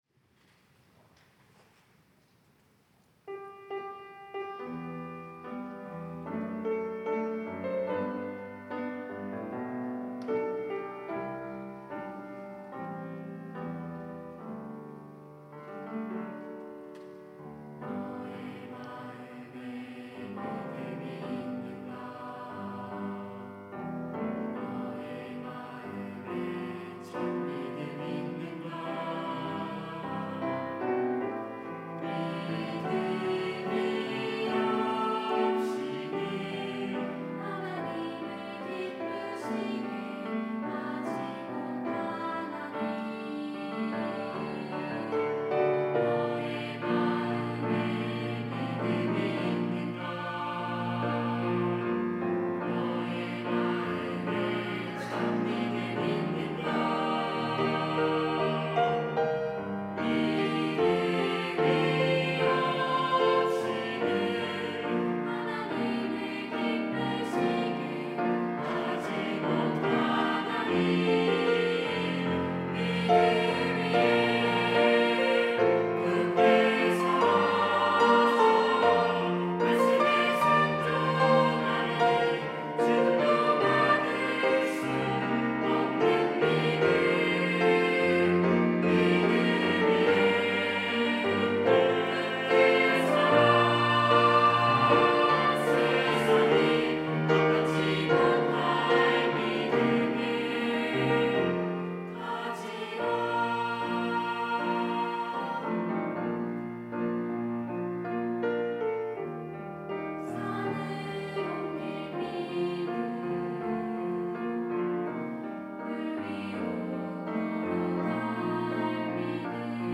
특송과 특주 - 믿음 위에 서라